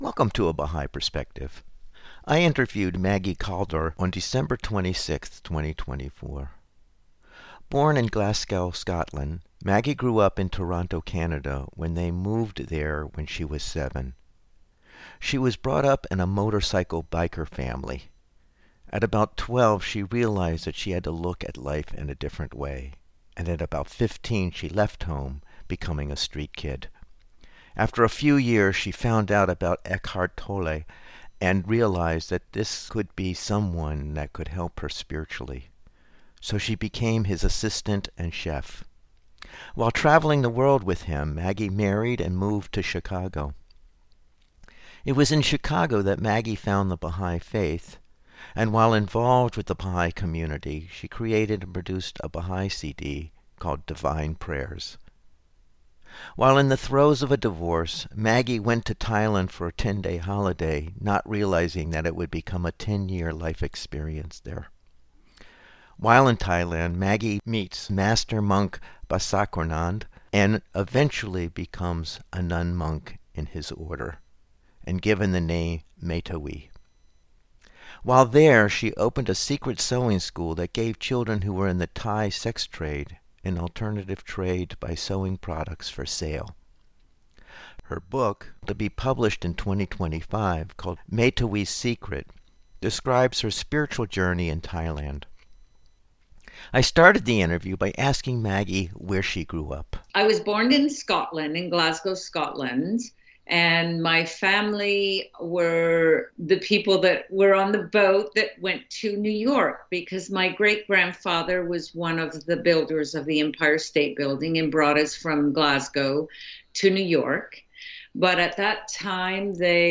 A weekly radio broadcast of biographical interviews of people who have either chosen the BAHÁÍI FAITH as a way of life or who have a relationship with the BAHÁÍI FAITH.